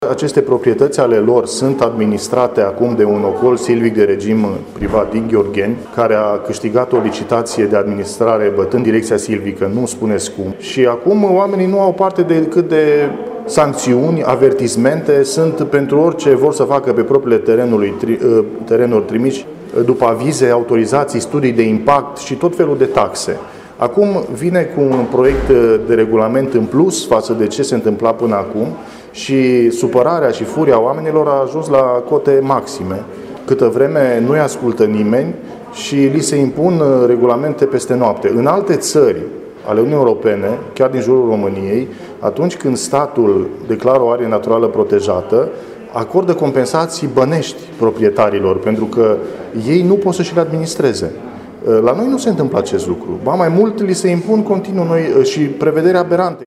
Senatorul PNL de Mureș Marius Pașcan spune că a făcut numeroase interpelări la Ministerul Mediului în care a sesizat problemele celor de pe Valea Mureșului.